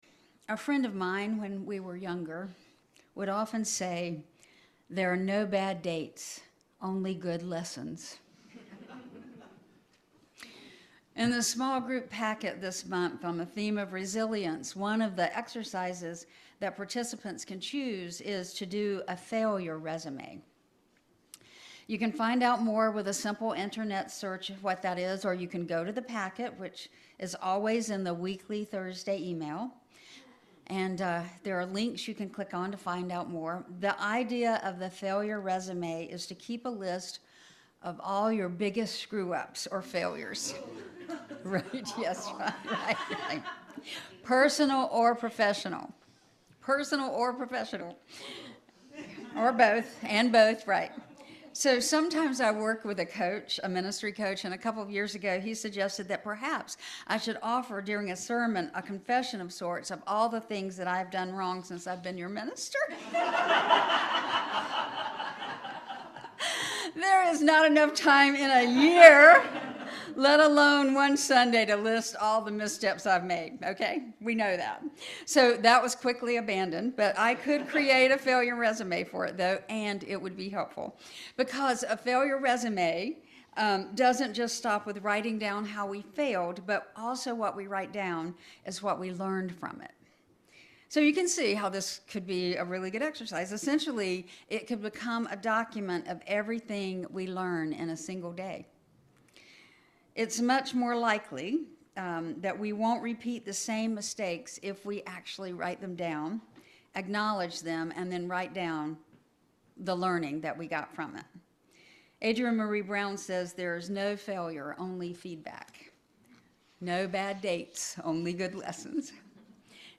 This sermon explores resilience as a collaborative effort rather than a solitary pursuit of toughness.